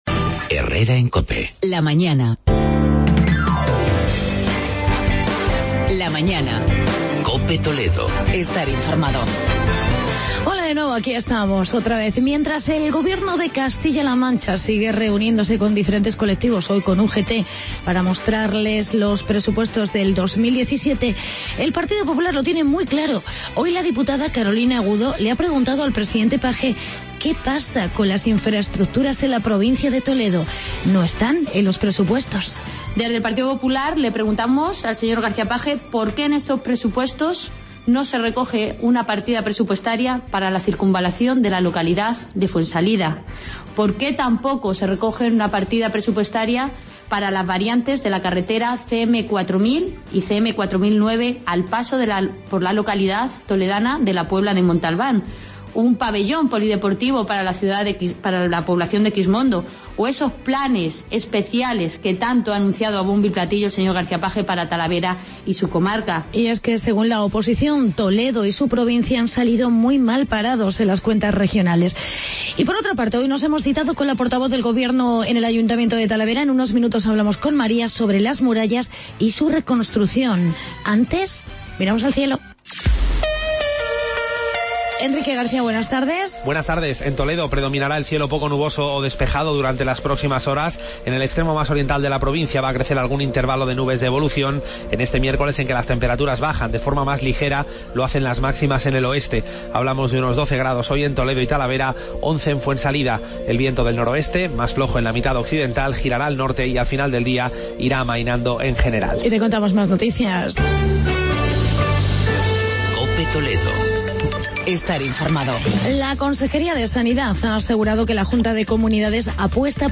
Actualidad y entrevista con María Rodríguez, portavoz gobierno del Ayto de Talavera de la Reina.